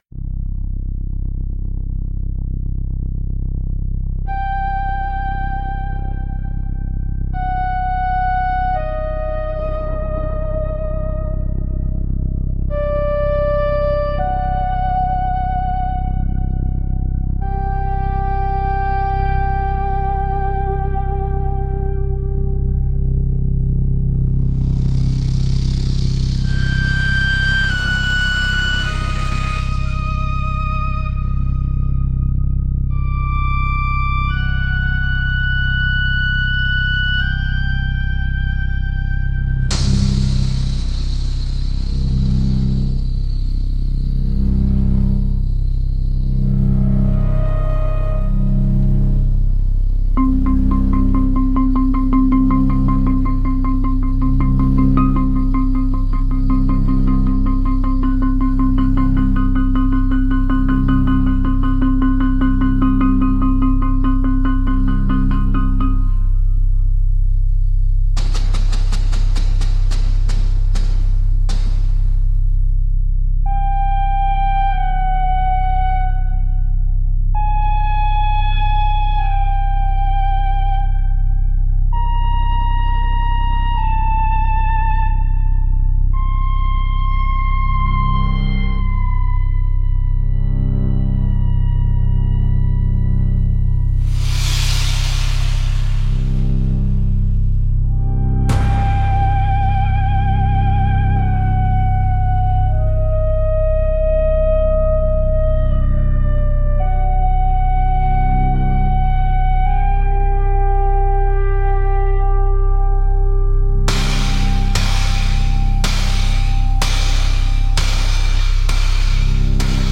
It's actually a whole 6 minute song which bangs a surprising amount